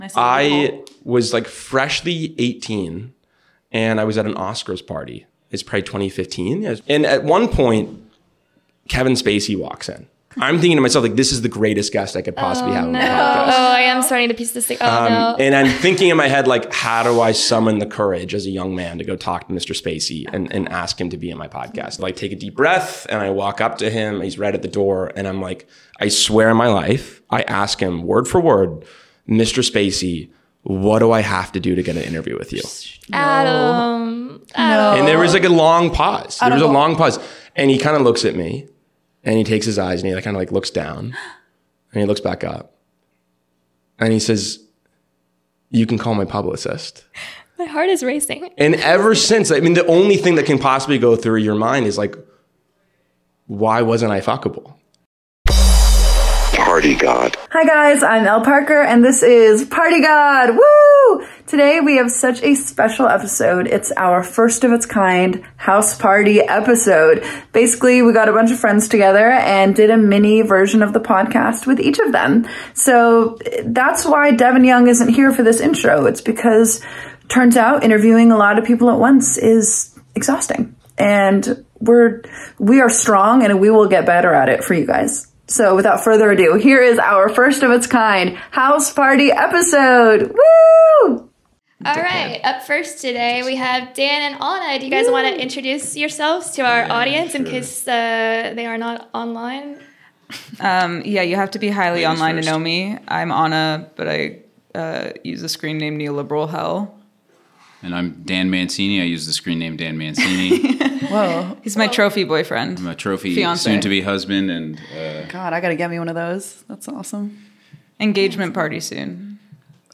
Our special “house party” episode ft. mini interviews